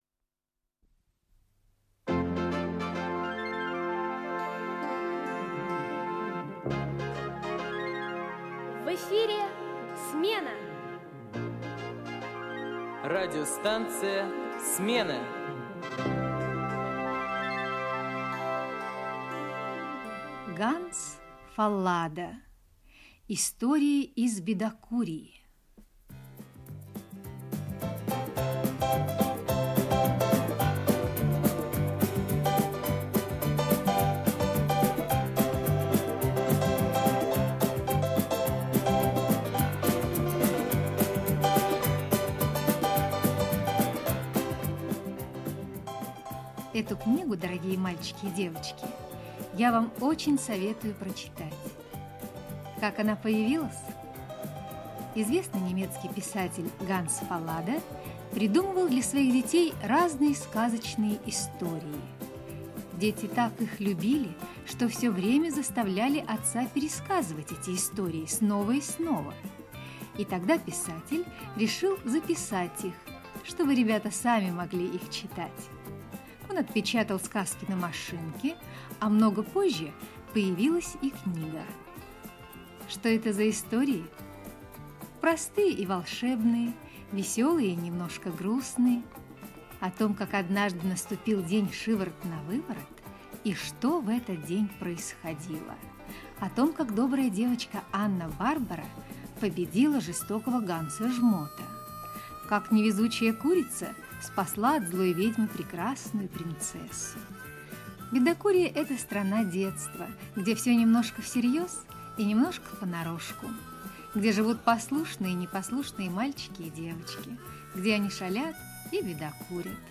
Про мышку Рваное ушко - аудиосказка Фаллада - слушать онлайн